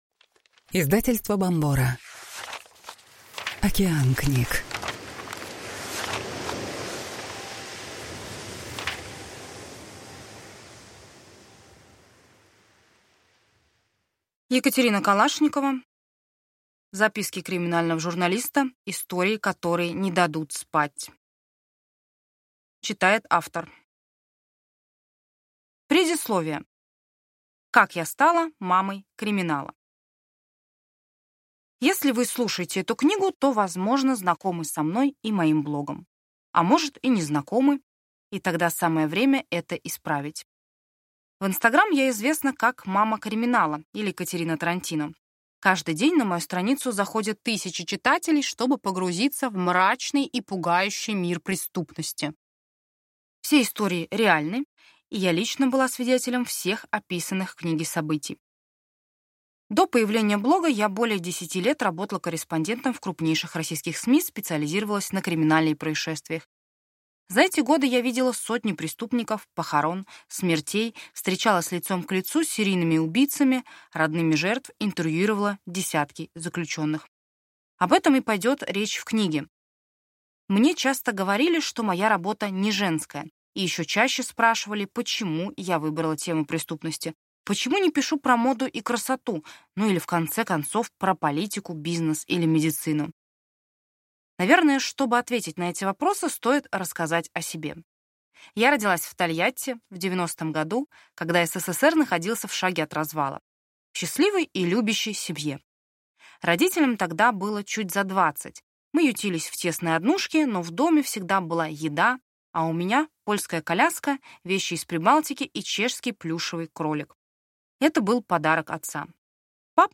Аудиокнига Записки криминального журналиста.